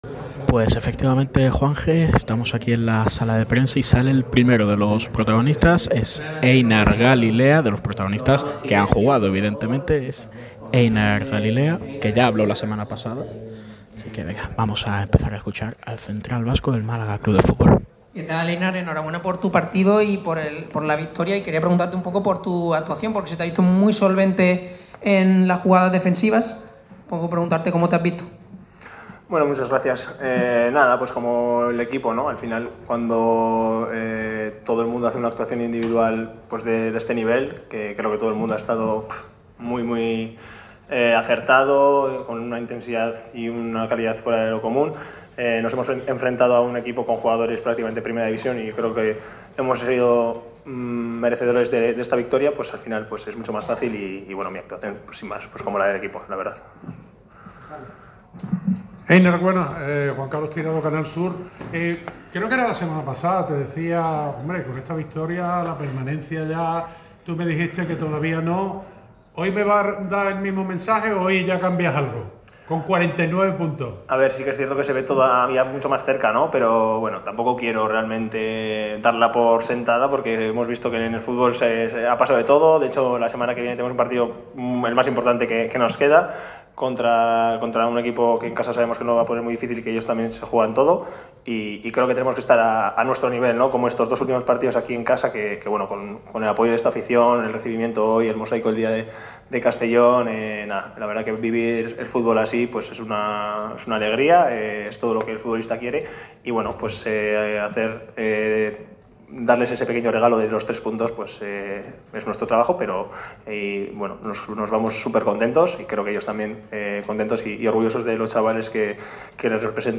El central se mostró muy contento por cómo jugó el equipo y considera que lo único que le faltó a la primera parte era que el equipo blanquiazul hubiera conseguido más renta en el marcador. «Hemos merecido ganar ante jugadores de Primera División«, ha asegurado el central en la sala de prensa de La Rosaleda.